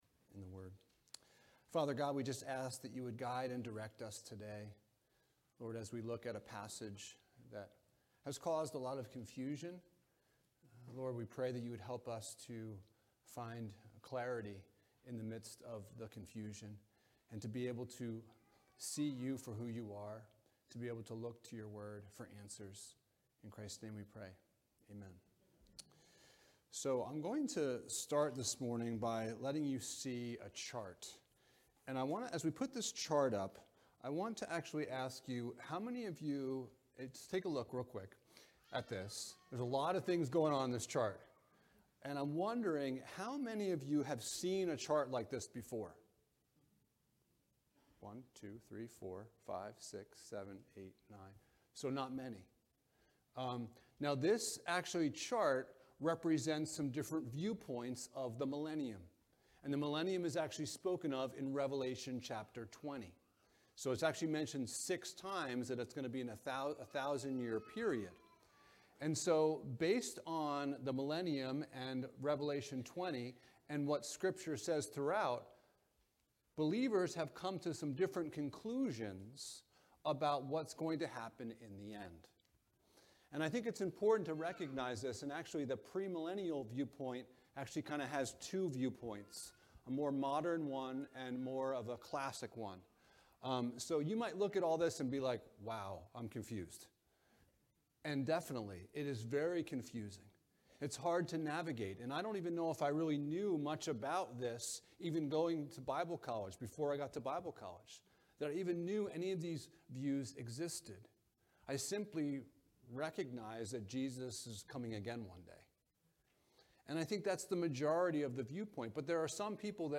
6-10 Service Type: Sunday Morning « What Are You Waiting For?